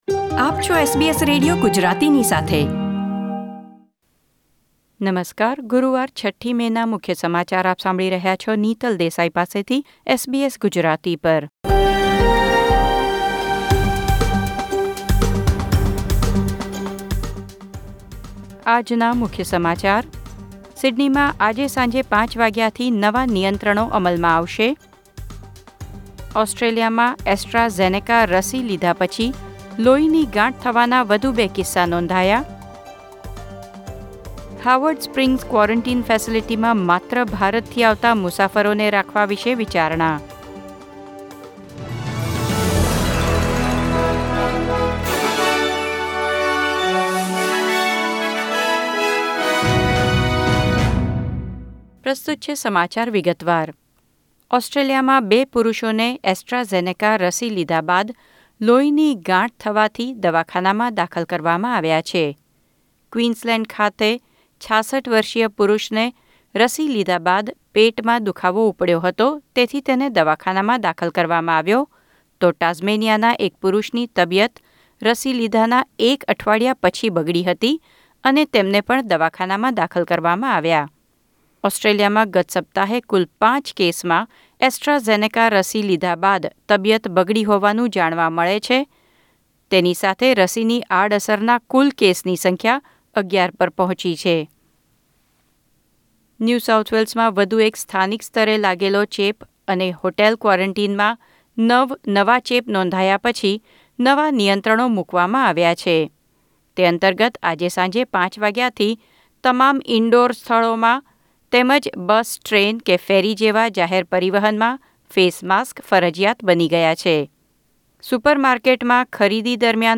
SBS Gujarati News Bulletin 6 May 2021